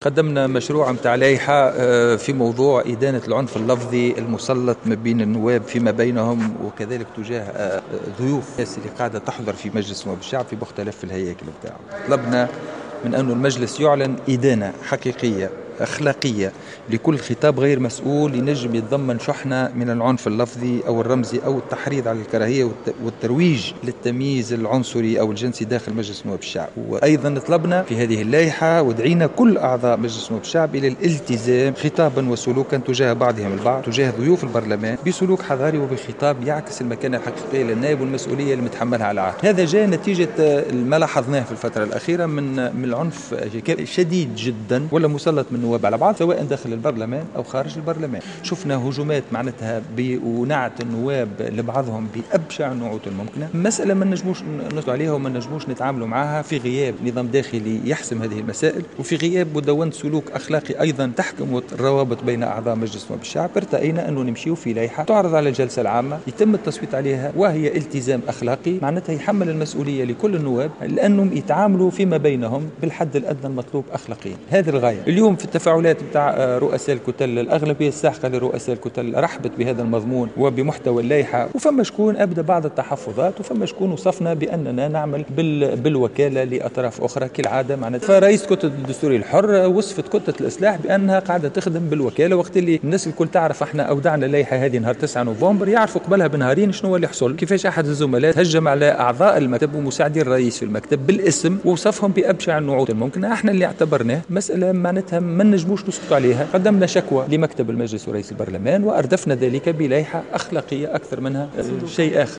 و قال رئيس الكتلة حسونة الناصفي في تصريح للجوهرة أف أم ، إن اللائحة طالبت مجلس النواب بإعلان إدانة حقيقية و أخلاقية لكل خطاب غير مسؤول يتضمن شحنة من العنف اللفظي أو الرمزي أو التحريض على الكراهية و الترويج للتمييز العنصري أو الجنسي داخل البرلمان.